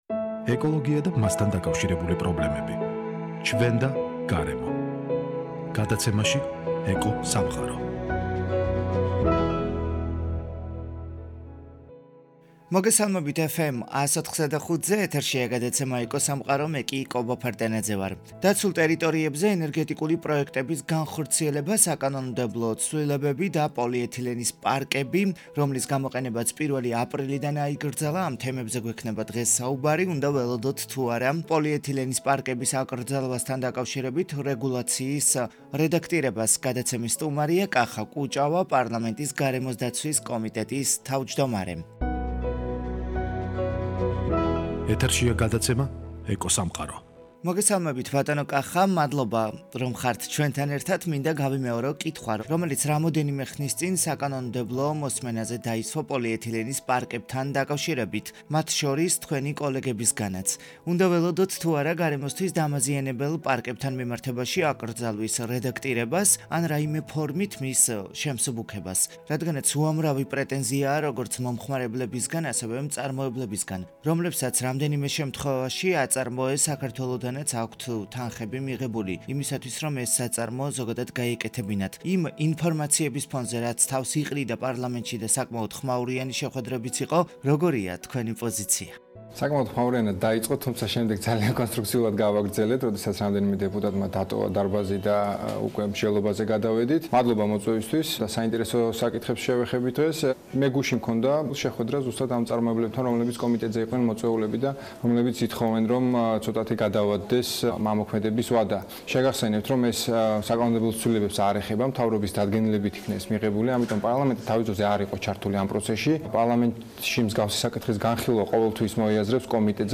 დაცულ ტერიტორიებზე ენერგეტიკული პროექტების განხორციელება, საკანონმდებლო ცვლილებები და პოლიეთილენის პარკები- რომლის გამოყენებაც პირველი აპრილიდან აიკრძალა. უნდა ველოდოთ თუ არა პოლიეთილენის პარკების აკრძალვასთან დაკავიშირებული რეგუალციის რედაქტირებას? გადაცემის სტუმარი:  კახა კუჭავა - პარლამენტის გარემოს დაცვის კომიტეტის თავმჯდომარე.